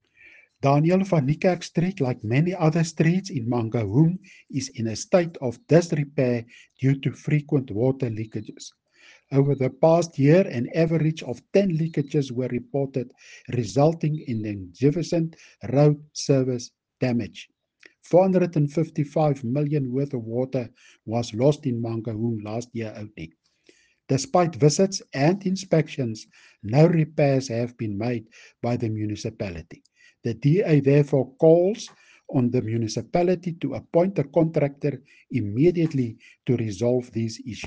Afrikaans soundbites by Cllr Hennie van Niekerk and